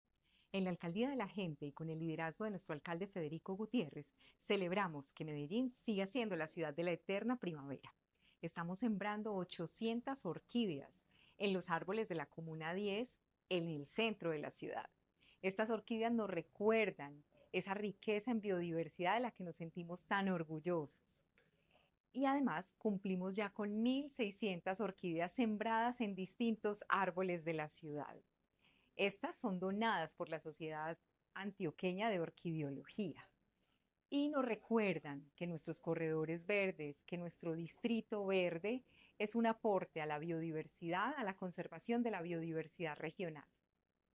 Declaraciones de la secretaria de Medio Ambiente, Marcela Ruiz.
Declaraciones-de-la-secretaria-de-Medio-Ambiente-Marcela-Ruiz.-Siembra-de-orquideas-.mp3